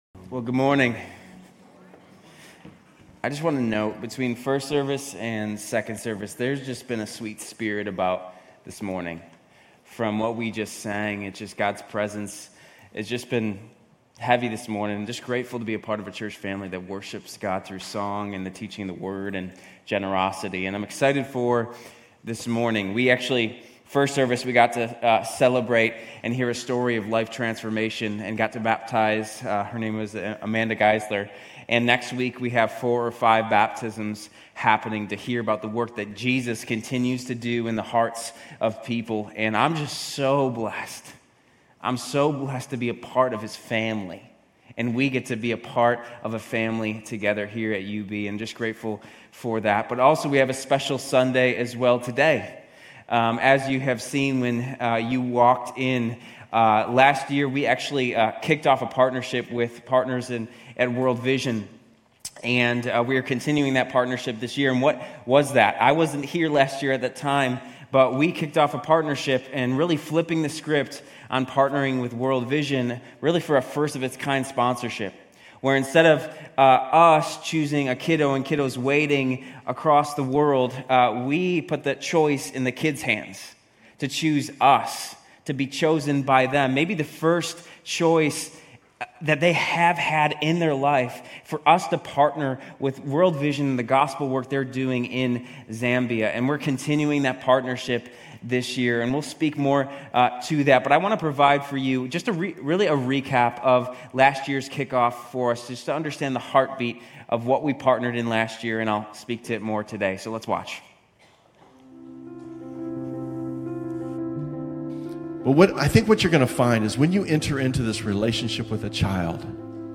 Grace Community Church University Blvd Campus Sermons World Vision Chosen Apr 22 2024 | 00:28:59 1x 00:00 / 00:28:59 Subscribe Share RSS Feed Share Link Embed